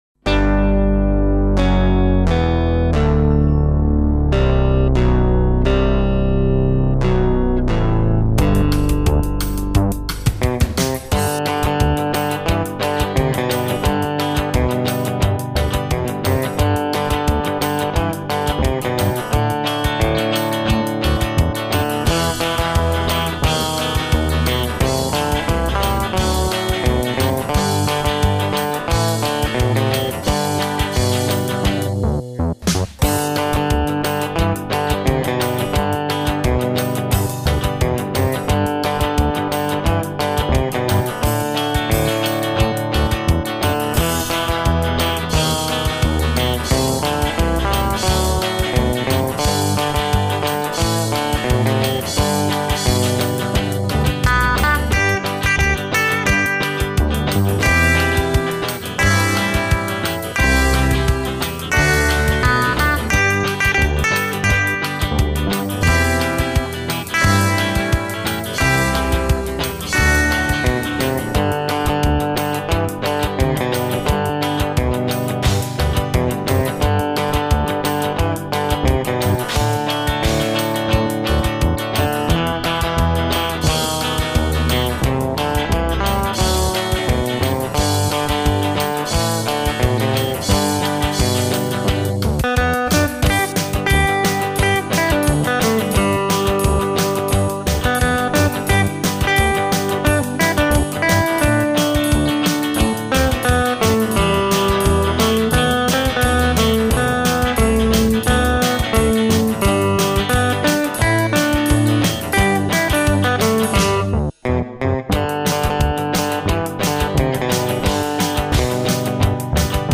Snímače jsou singly RP 94 R. M. Pickups, spínače jsou vlastní konstrukce.
Použité materiály, hardware a elektrické zapojení jí dodávají požadovaný singlový, ale plný konkretní zvuk s mnoha možnostmi volby barvy. Zajímavý zvuk má zejména při sepnutí přepínače fází.